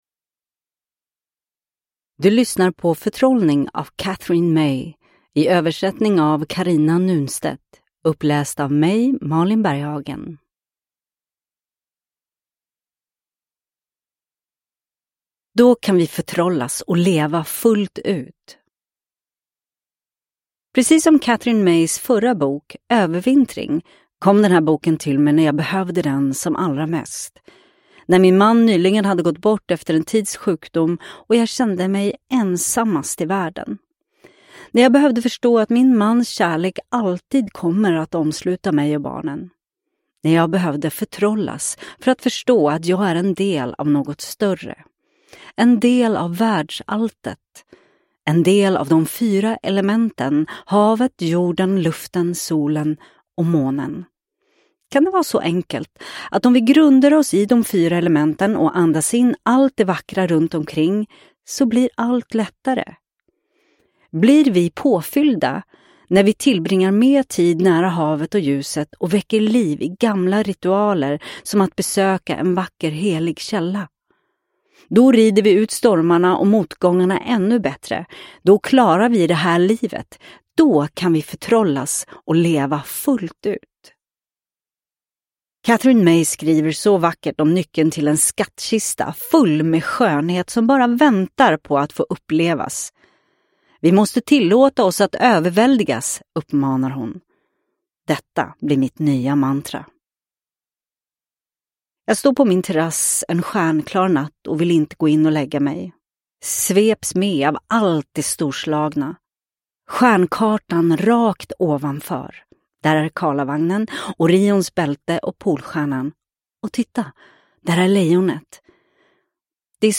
Förtrollning – Ljudbok – Laddas ner
Uppläsare: Malin Berghagen